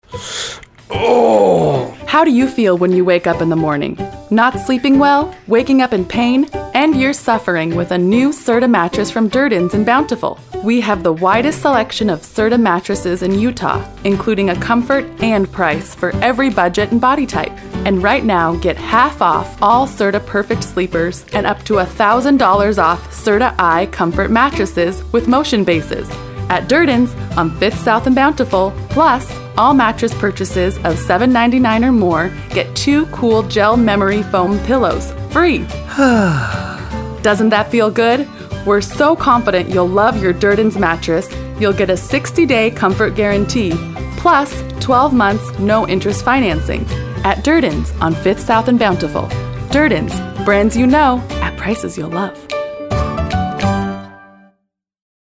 And then there was the time I was in a radio commercial…